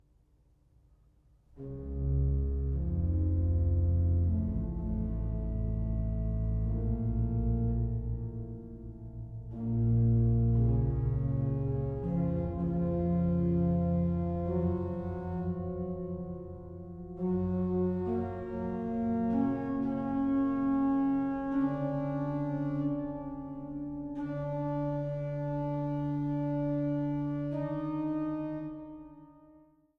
Andante maestoso